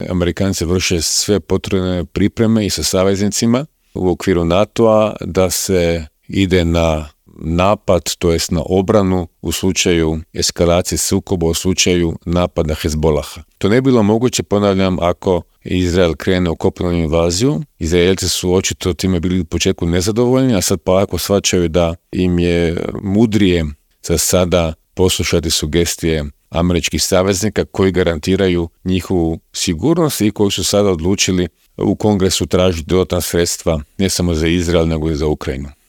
Aktualnu situaciju na Bliskom istoku, ali i u Europi, u Intervjuu Media servisa analizao je bivši ministar vanjskih i europskih poslova Miro Kovač.